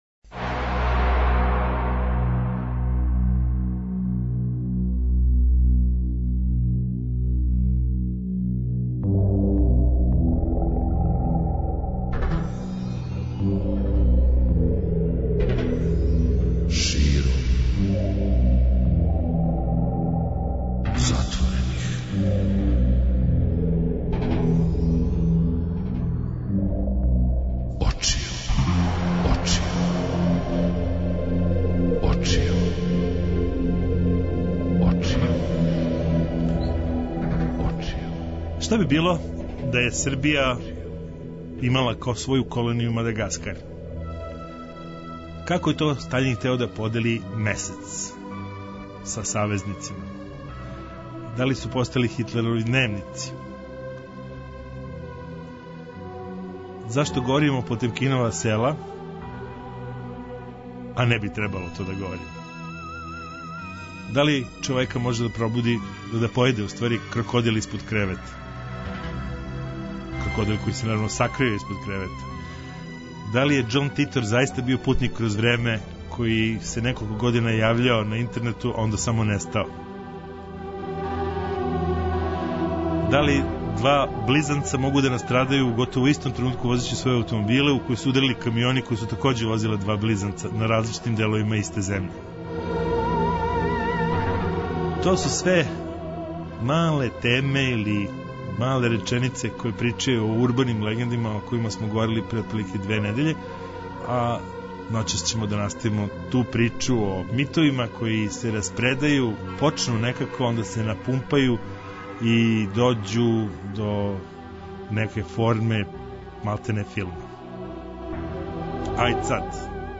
Ноћни програм Београда 202.